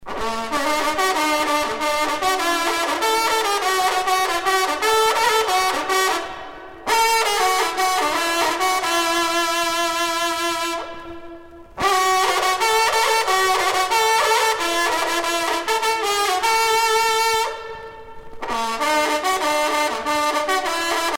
trompe - Fanfares et fantaisies de concert
circonstance : vénerie
Pièce musicale éditée